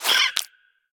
Sfx_creature_babypenguin_hold_equip_above_01.ogg